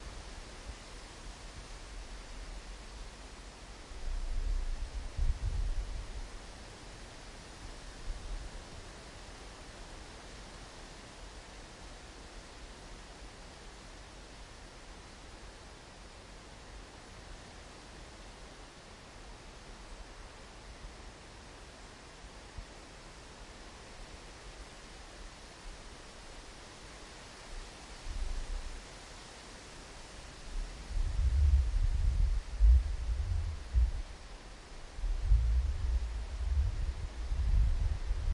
叶子
描述：我走到外面踩着树叶
Tag: 户外